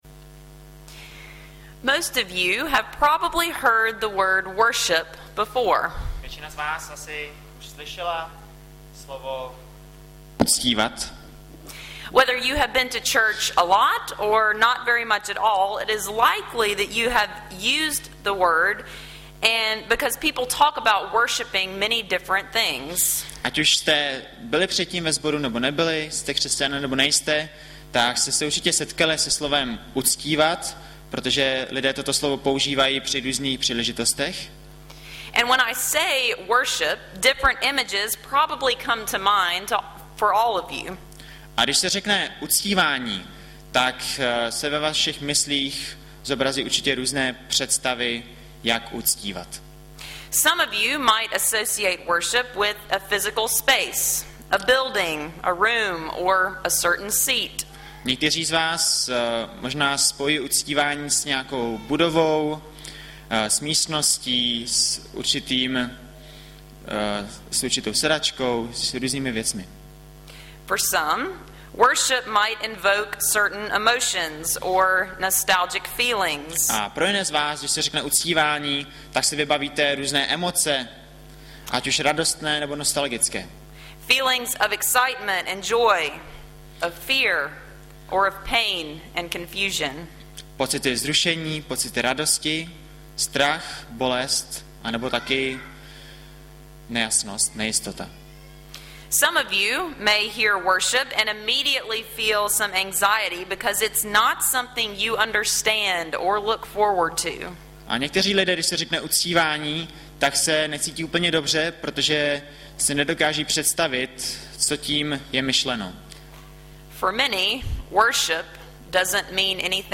- Kol. 3,12-17 Audiozáznam kázání si můžete také uložit do PC na tomto odkazu.